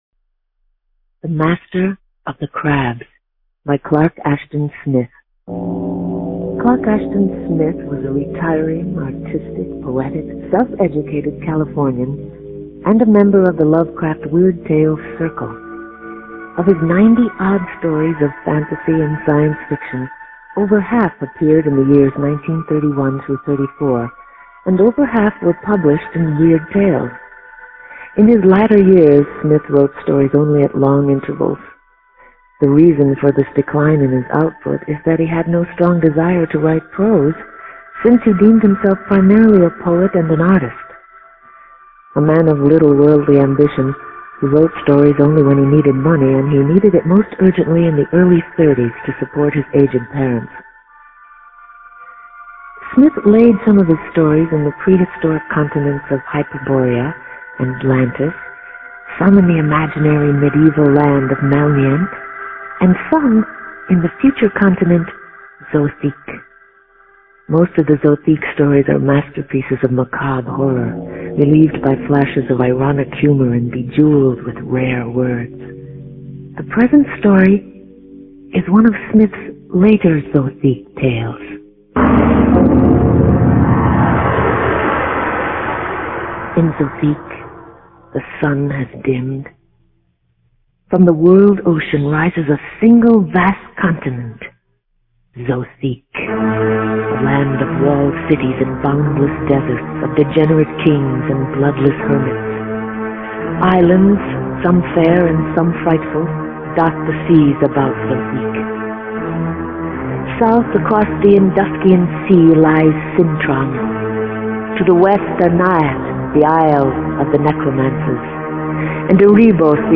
Performed by Third Ear Radio Theater